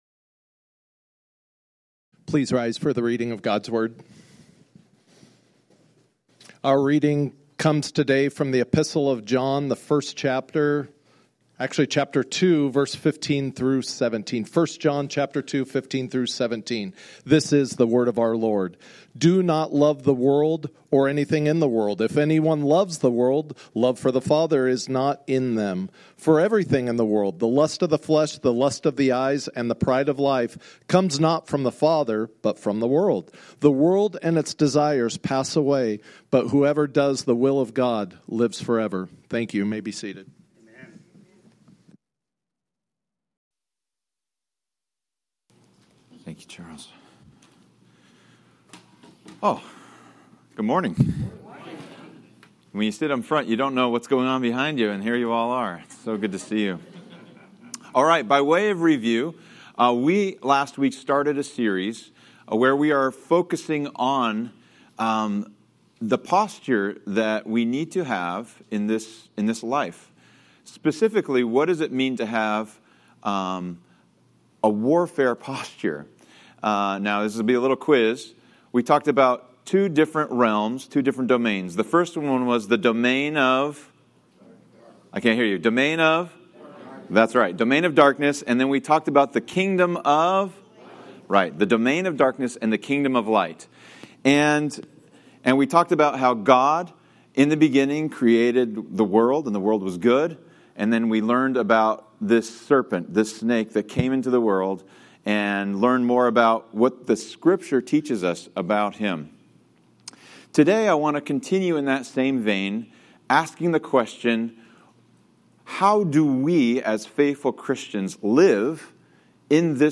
Sermons - Grace Church - Pasco